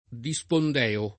[ di S pond $ o ]